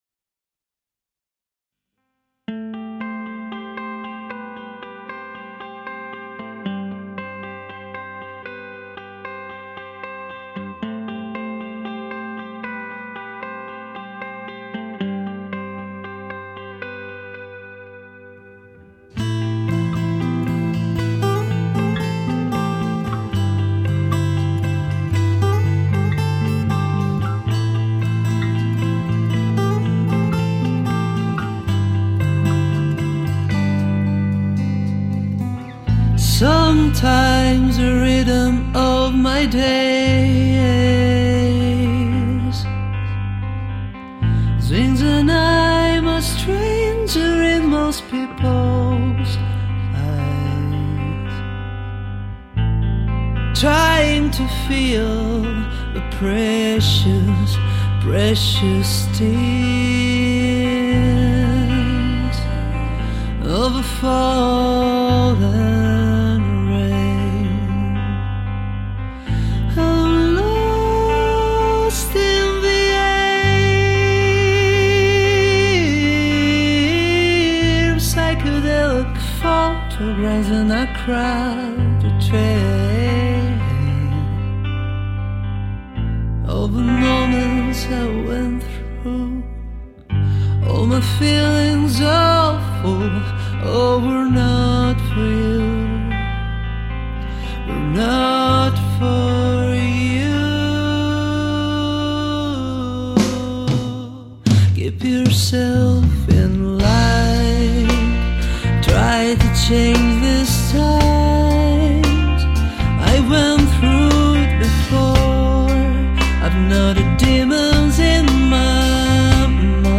alternative-rock